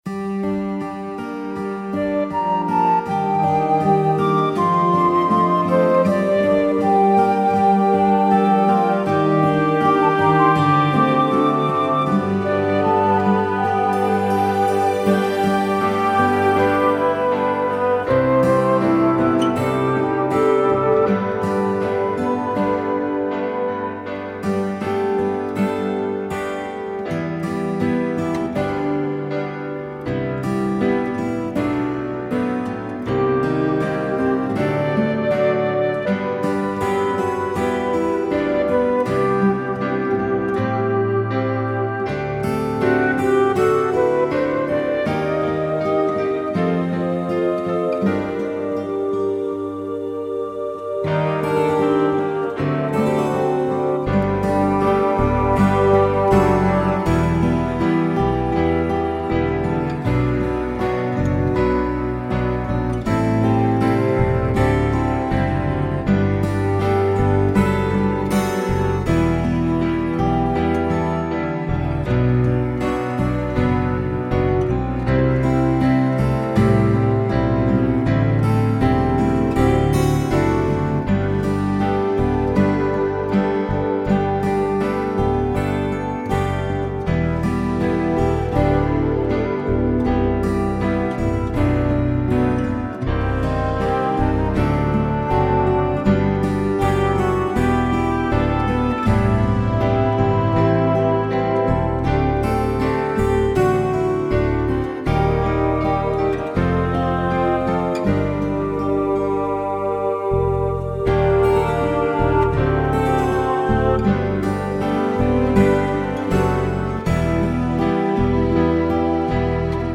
My Song Unsung Arrangement 2014
my-song-unsung-bonus-arrangement.mp3